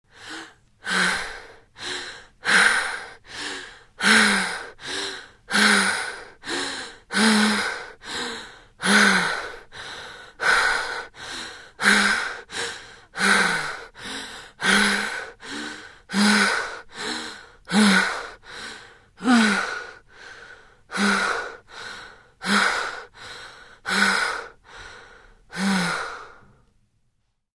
Звуки отдышки
Затрудненное дыхание у девушки